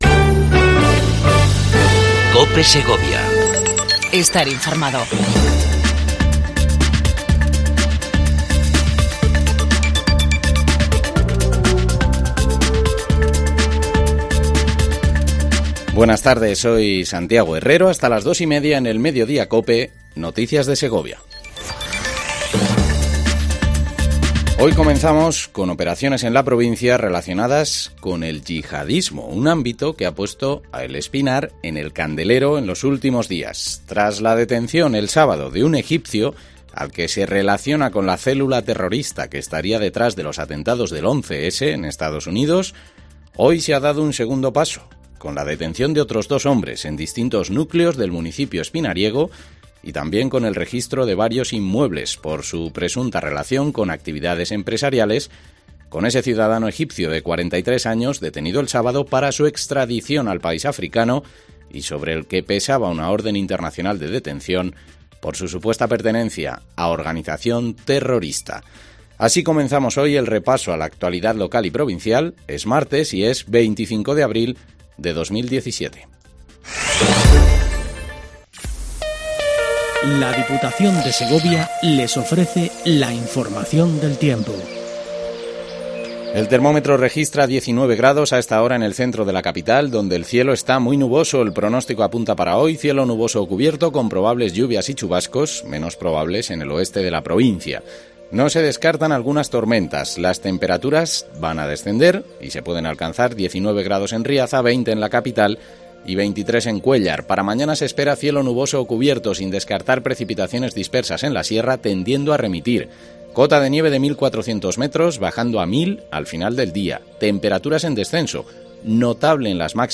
INFORMATIVO MEDIODIA COPE EN SEGOVIA 25 04 17